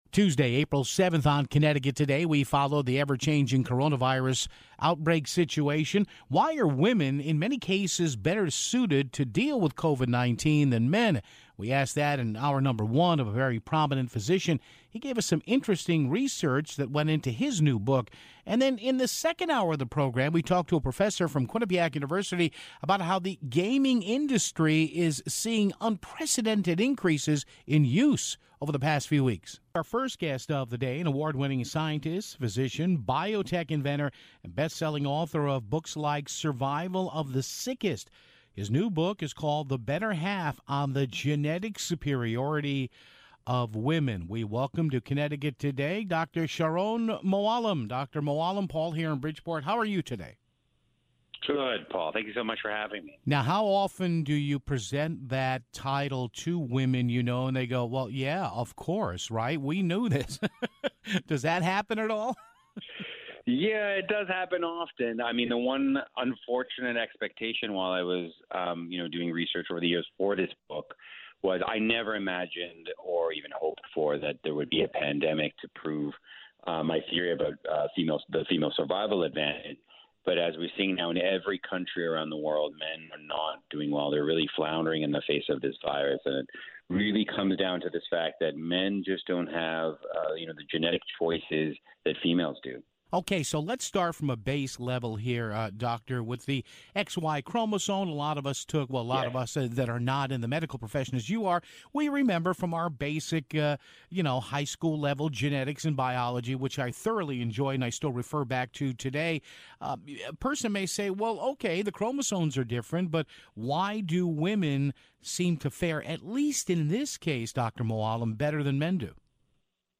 a conversation on how women may have a genetic advantage over men when it comes to beating the coronavirus